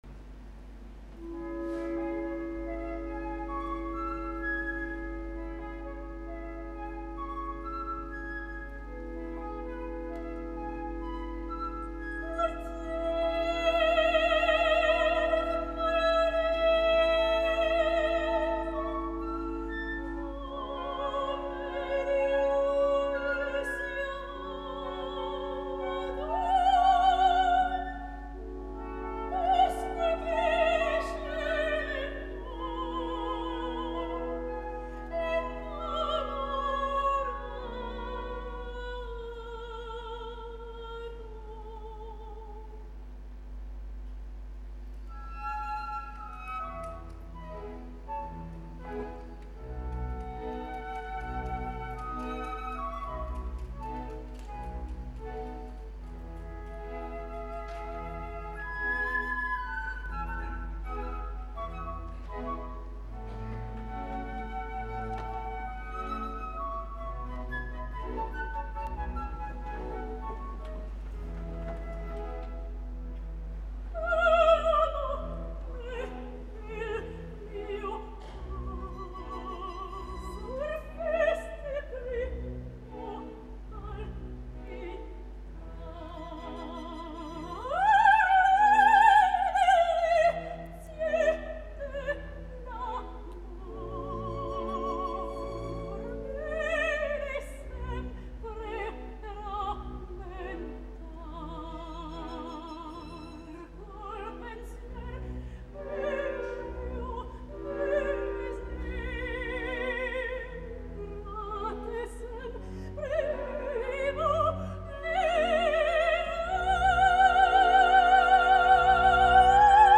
A Petibon, al contrari que a Dessay, la veu se li ha eixamplat una mica, esdevenint una lírica lleugera amb un cert cos que juntament amb l’adequada intenció fa que el seu cant de sigui més interessant. Em pensava que seria pitjor i malgrat alguna que altre molesta fixació i algunes frases no del tot afinades, la seva Gilda sense ser per llençar coets és audible.
Gilda Patricia Petibon
Bayerisches Staatsorchester
Director musical Marco Armiliato
15 de desembre de 2012, Nationaltheater, Munic